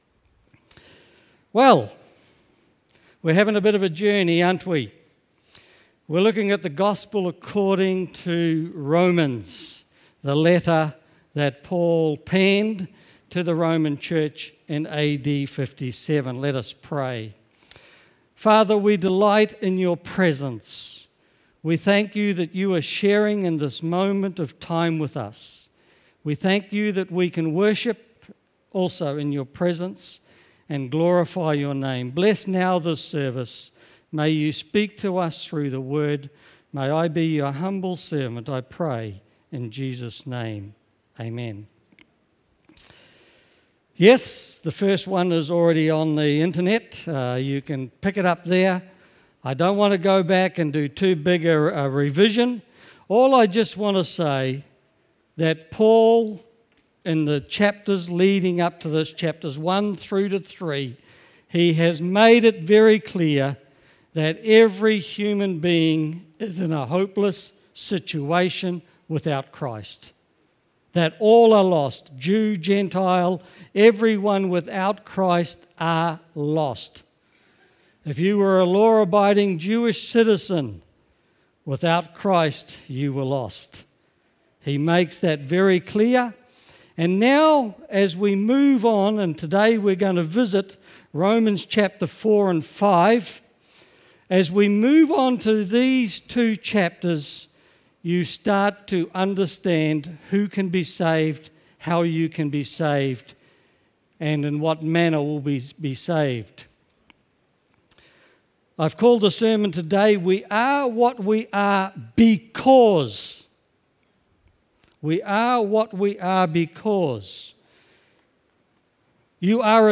(Series on Romans, Pt 2. Talk given Jan 28, 2017)